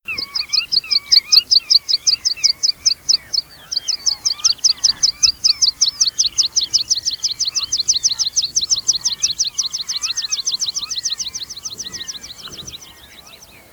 graspieper
🇬🇧 English: meadow pipit
graspieper_zang.mp3